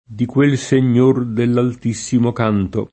signore [Sin’n’1re] s. m. — tronc.: sa lei, signor mio, come la pensi l’imperatore, in questo momento?